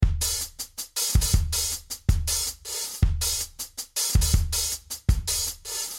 基本俱乐部节拍120 Bpm
描述：基本俱乐部节拍120 bpm
标签： 120 bpm Dance Loops Drum Loops 1.35 MB wav Key : Unknown
声道立体声